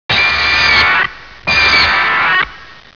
Hear Gigan roar!